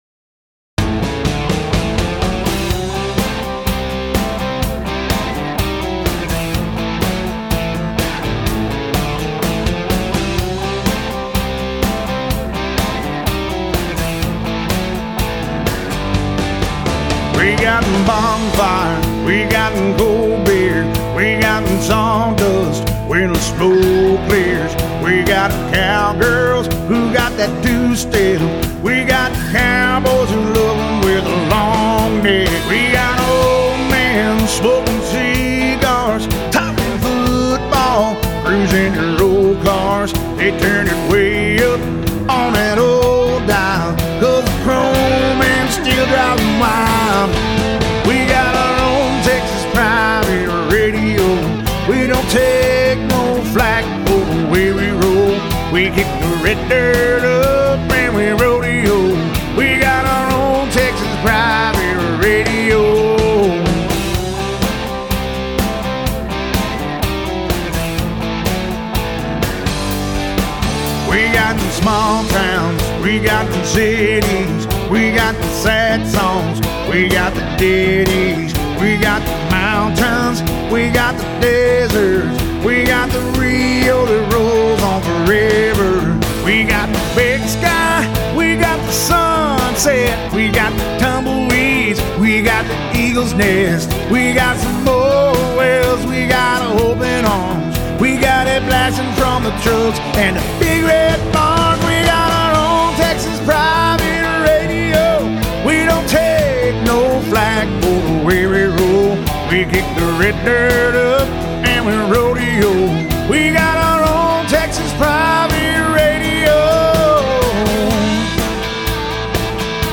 Fiddle
Lead Guitar
Drums
Bass